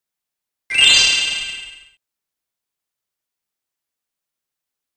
Gem (Spyro) Sound Effect MP3 Download Free - Quick Sounds
game